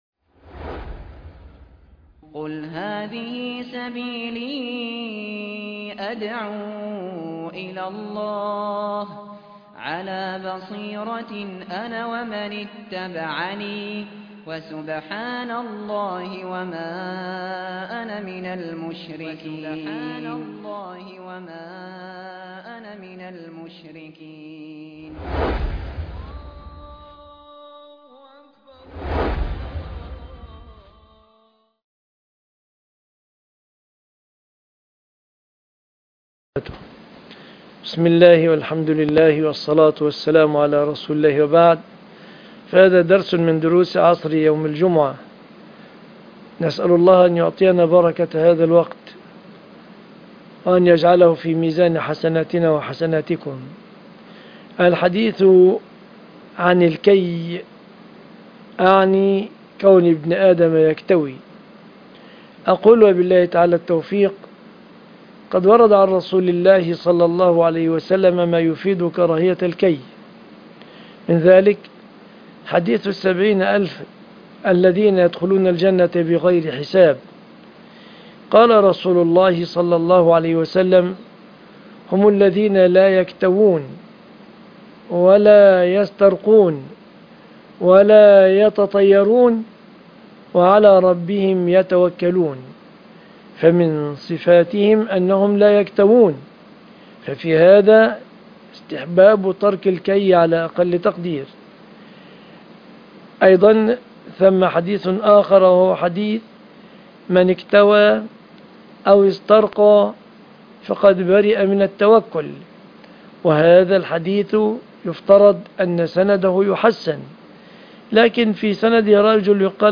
عنوان المادة ( 19/10/2018 ) الكي ( كون ابن ادم يكتوي ) ( درس عصر يوم الجمعة )